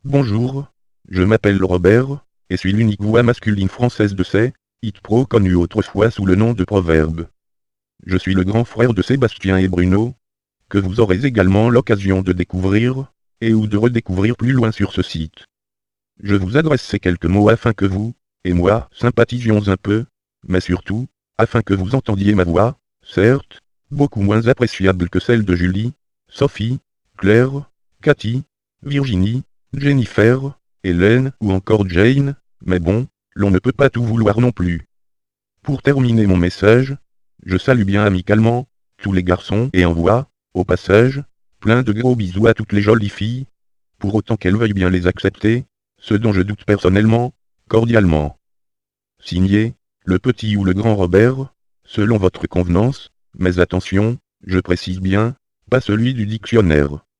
Texte de démonstration lu par Robert, voix masculine française de Logisys SayItPro (Version 1.70)